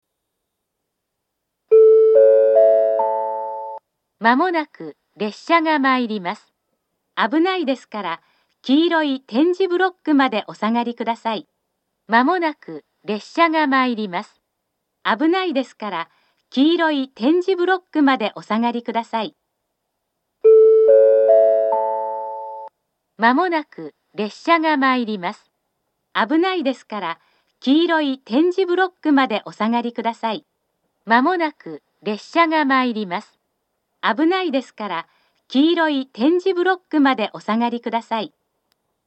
２番線下り接近放送